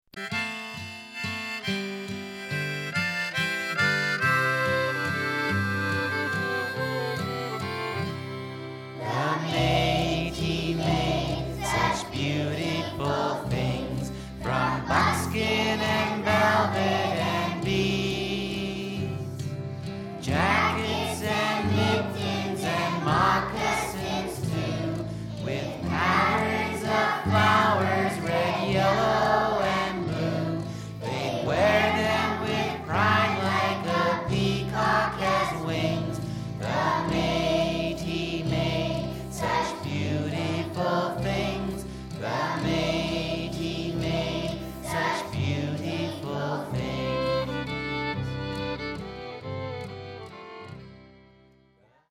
Lots of traditional Métis type fiddle music.